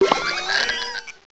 sovereignx/sound/direct_sound_samples/cries/farigiraf.aif at master
farigiraf.aif